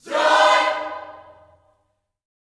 JOY CHORD5.wav